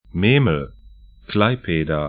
Pronunciation
Memel 'me:məl Klaipėda 'klaipeda lt Stadt / town 55°43'N, 21°07'E